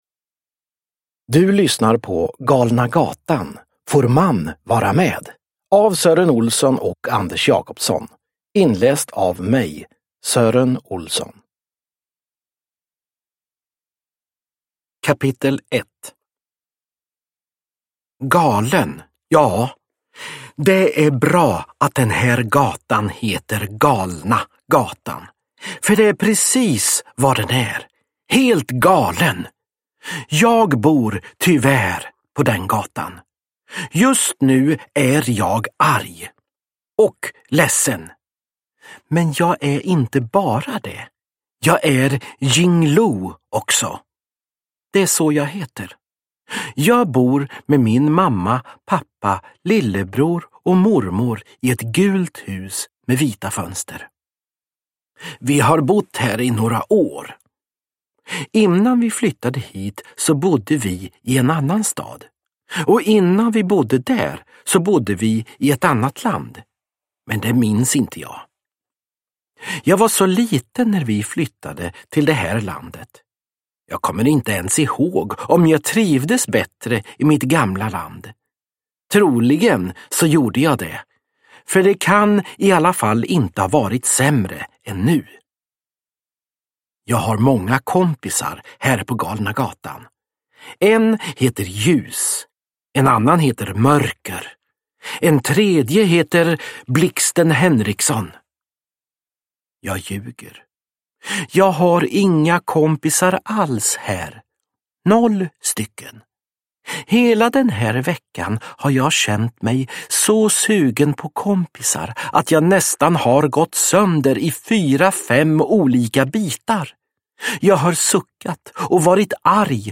Får Mann vara med? (ljudbok) av Sören Olsson